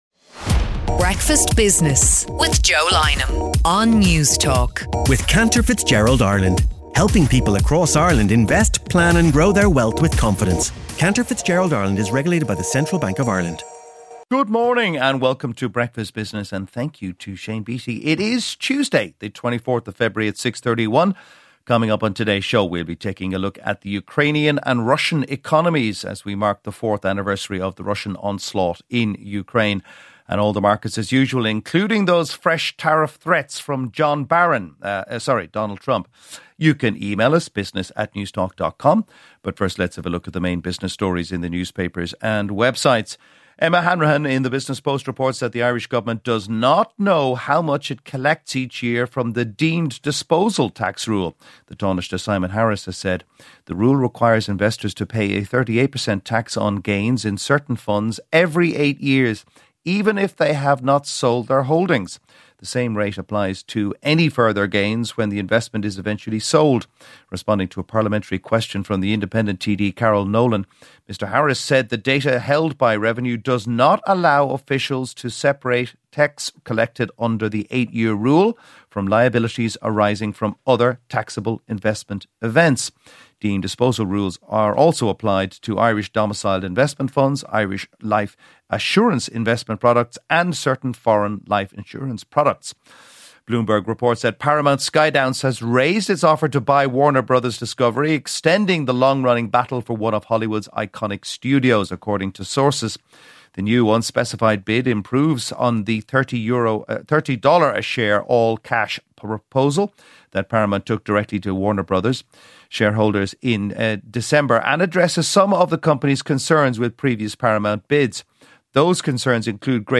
Tuesday's business news headlines